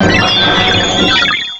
Cri_0493_DP.ogg